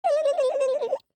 turkey-v1.ogg